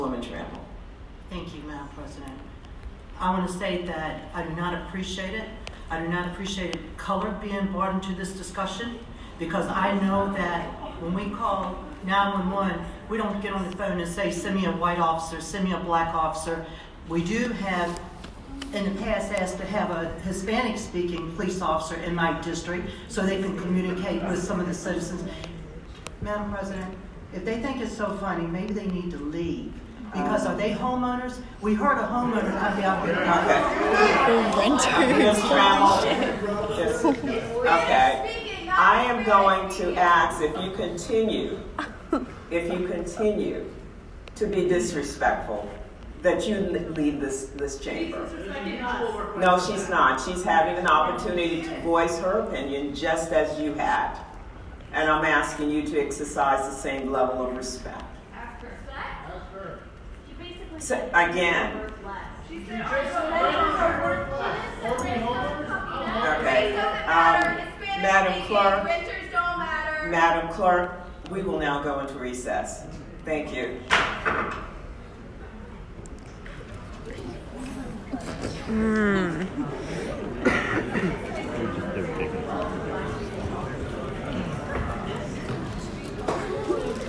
LISTEN: City Council meeting over proposed VCU police changes descends into uproar
Councilwoman Reva Trammell, who voted in favor of the ordinance to expand the VCU Police Department’s Jurisdiction, spoke about the expansion proposal prior to a recess, which was called after an argument broke out on the floor of the council.
Trammel-and-constituents-argue-over-proposed-ordinance.wav